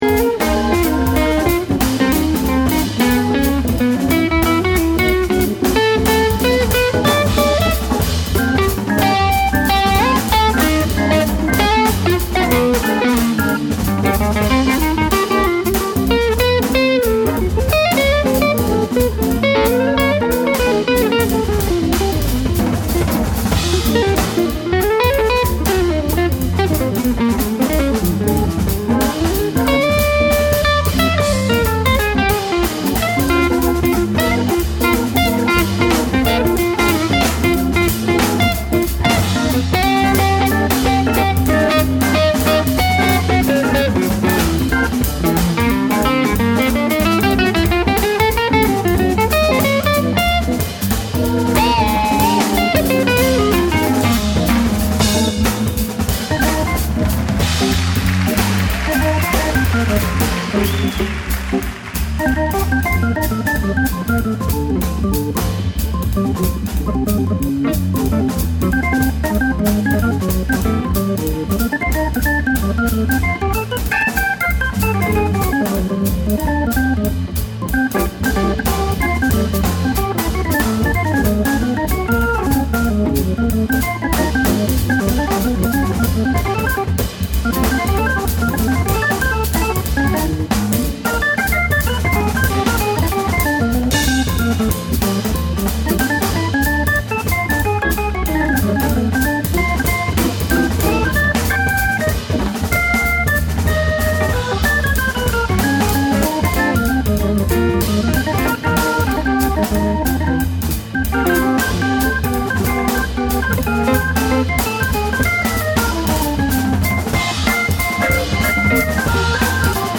enregistré en public
Le premier plus calssique